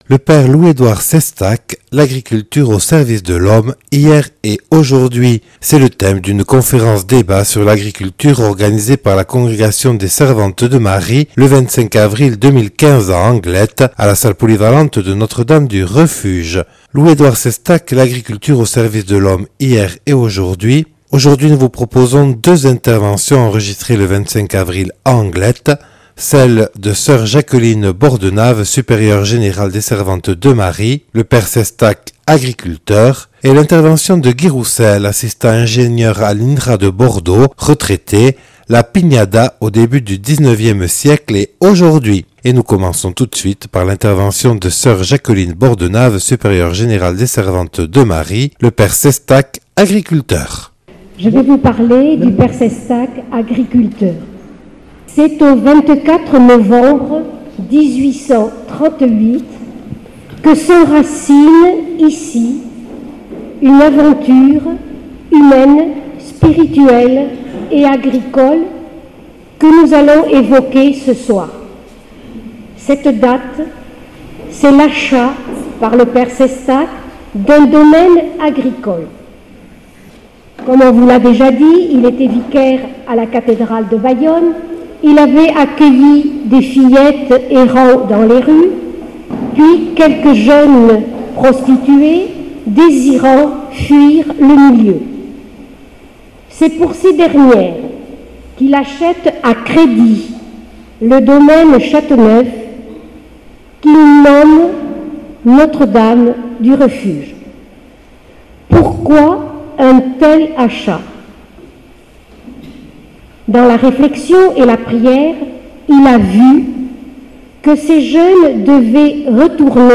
Enregistré le 25/04/2015 à Notre-Dame du Refuge à Anglet.